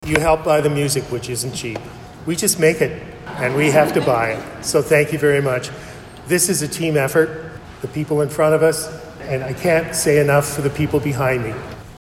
It was a special day Sunday afternoon for members of the Belleville Choral Society and an audience of more than 300.
St Andrew’s Presbyterian Church in Belleville hosted the 60th anniversary concert of BCS.